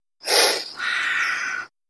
Chameleon Breaths. Close Perspective.